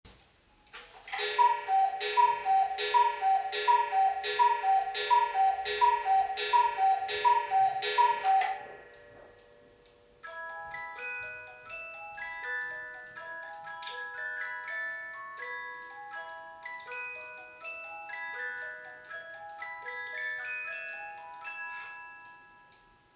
Chalet Kuckucksuhr mit Musikwerk
mit Kuckucksruf und Tonfeder (Gangdauer 1 Tag)